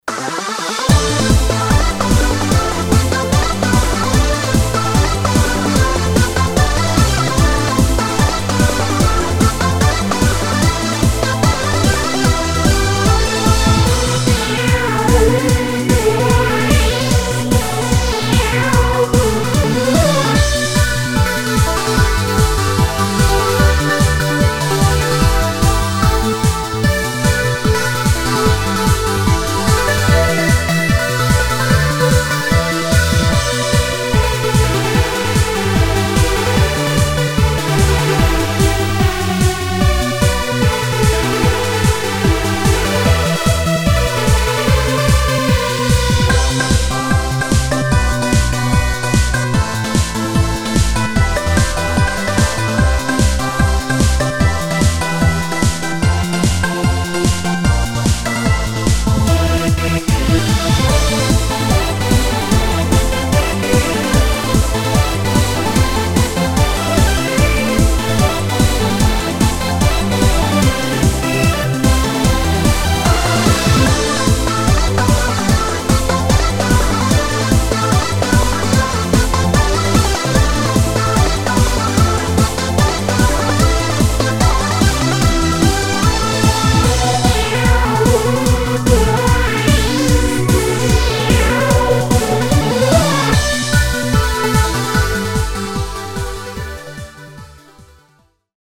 フリーBGM バトル・戦闘 4つ打ちサウンド